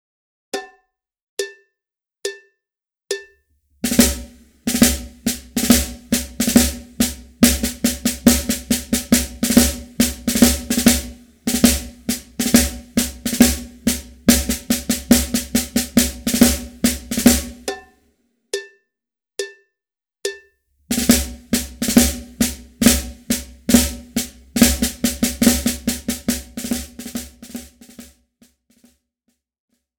Besetzung: Schlagzeug